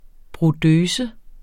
Udtale [ bʁoˈdøːsə ]